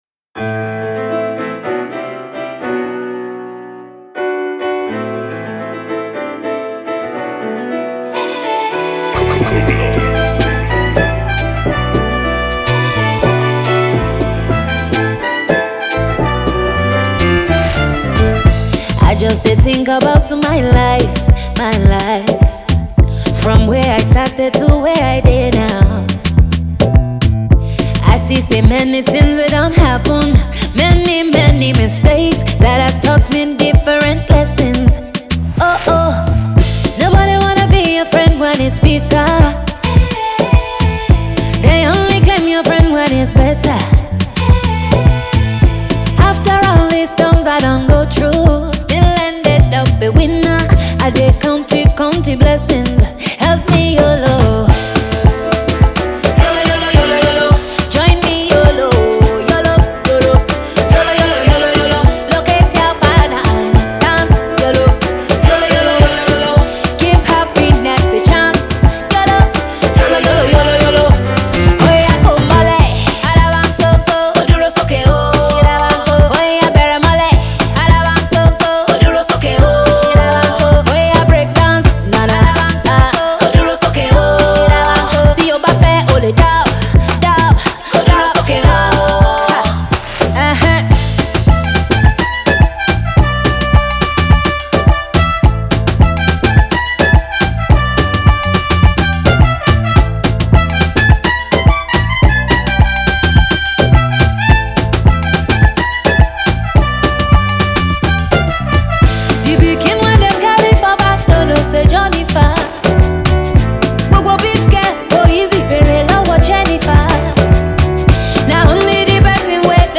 up-tempo rhythm with trumpets
catchy hooks and sing-along melodies.